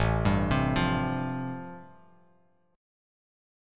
Am7#5 chord